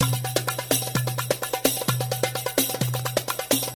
描述：阿拉伯语 西部达布卡
Tag: 128 bpm Ethnic Loops Drum Loops 970.43 KB wav Key : Unknown